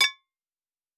pgs/Assets/Audio/Fantasy Interface Sounds/UI Tight 30.wav
UI Tight 30.wav